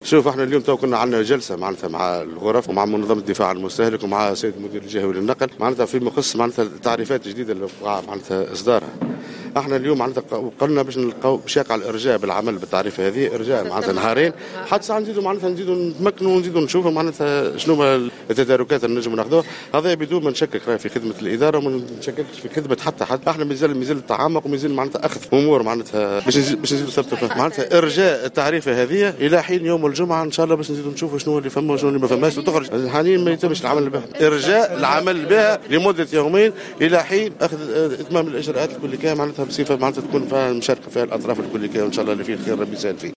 أكد والي سوسة، عادل الشليوي، في تصريح للجوهرة أف أم، أن جلسة جمعته اليوم الأربعاء، بممثلين عن منظمة الدفاع عن المستهلك والمدير الجهوي للنقل، تقرر خلالها إرجاء العمل بالتعريفة الجديدة للنقل الجماعي إلى غاية يوم الجمعة.